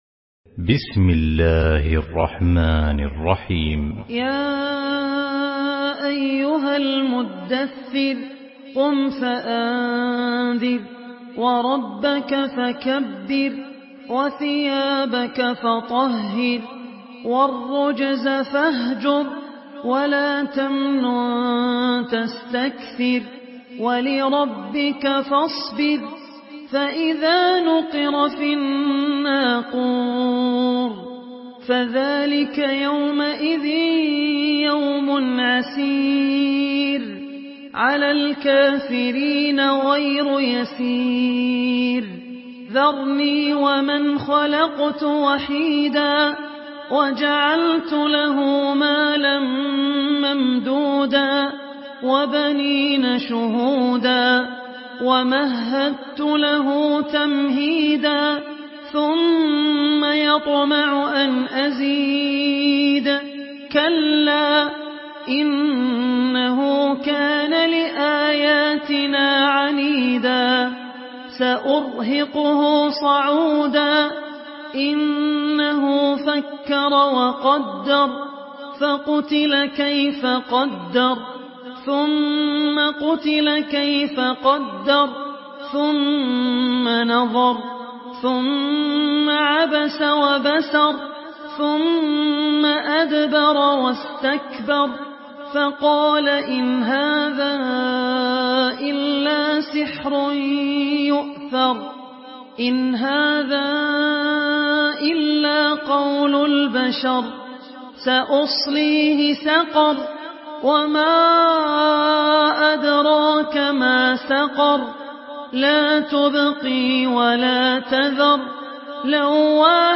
Surah আল-মুদ্দাস্‌সির MP3 by Abdul Rahman Al Ossi in Hafs An Asim narration.
Murattal Hafs An Asim